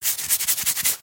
Звуки чесания